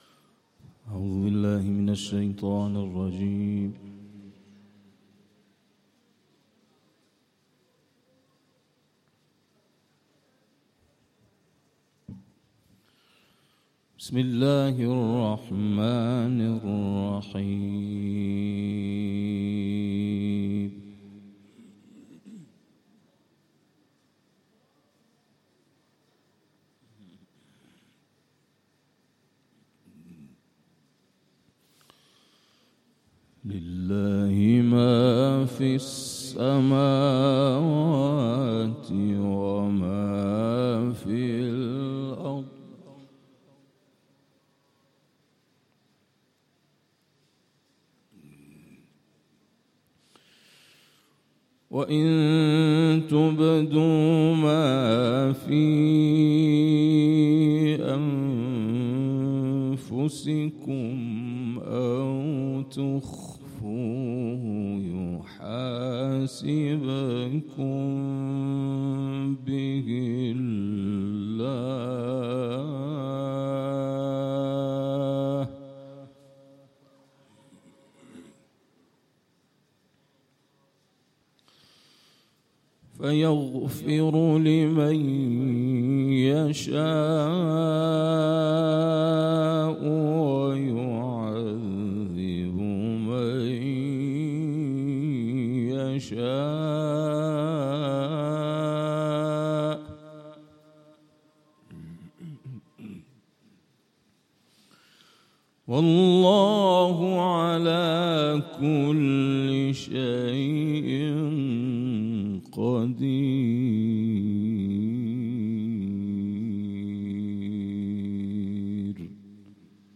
به گزارش ایکنا، در ادامه سلسله‌ برنامه‌های قرآنی دانشگاه صنعتی امیرکبیر، هفتمین محفل قرآنی تلاوت و تدبر این دانشگاه روز دوشنبه پنجم خردادماه در راستای برنامه دوشنبه‌های قرآنی برگزار شد.
اجرای گروه مدیحه‌سرایی و هم‌خوانی محراب بخش دیگری از برنامه‌های این محفل قرآنی بود.